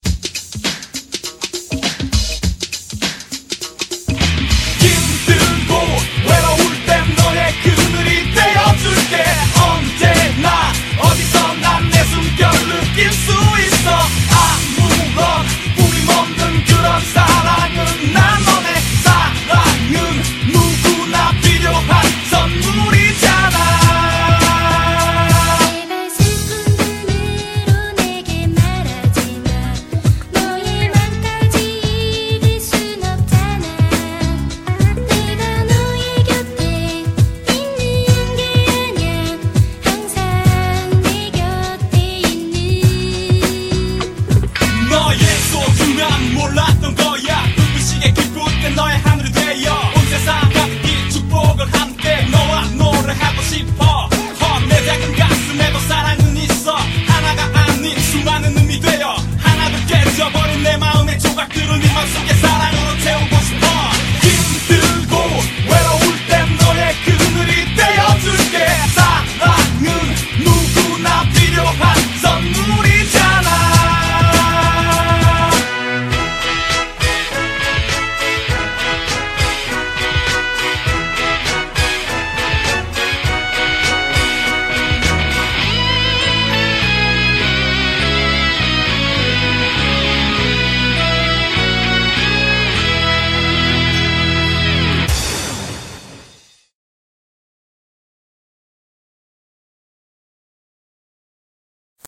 BPM101--1
Audio QualityPerfect (High Quality)